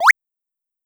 8bit Jump 03.wav